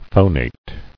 [pho·nate]